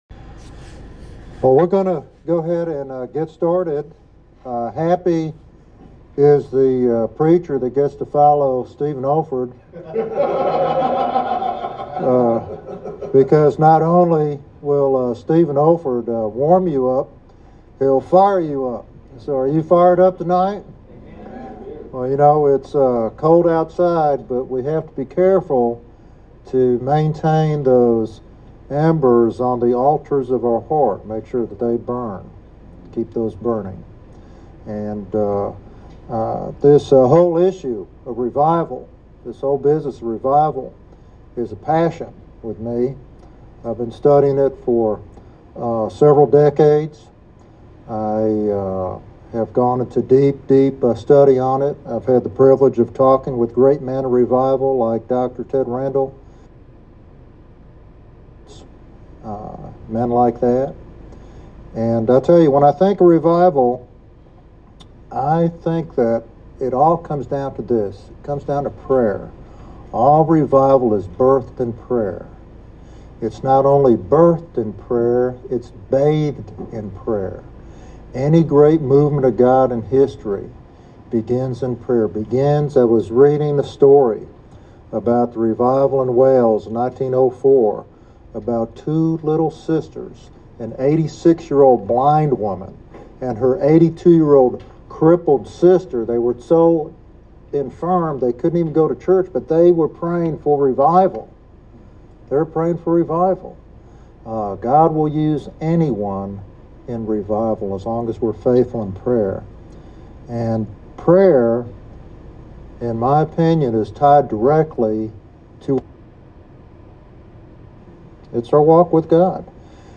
He emphasizes the accessibility of walking with God and the necessity of holiness, listening, and obedience in this journey. This sermon is a call to awaken the church and ignite a fresh movement of the Spirit.